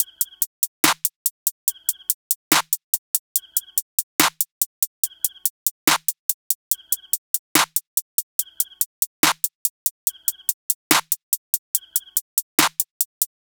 SOUTHSIDE_beat_loop_mafia_top_01_143.wav